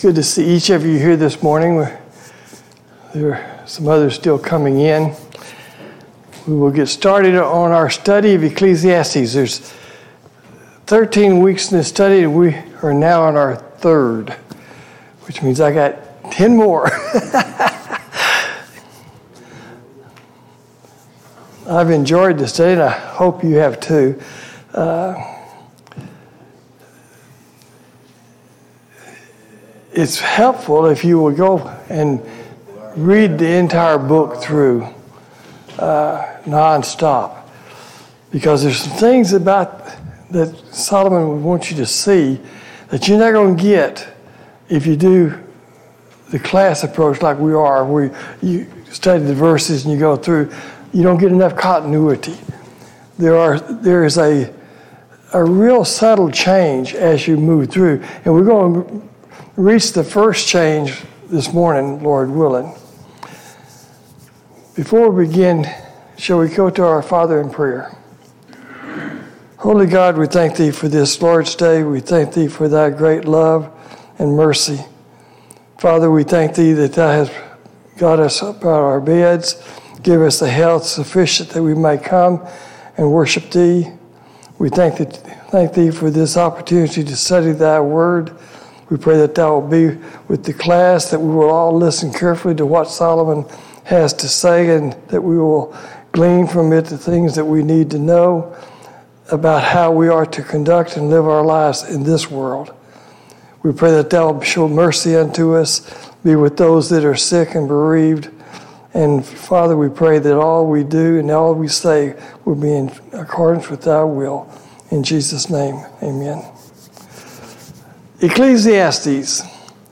Sunday Morning Bible Class « 36.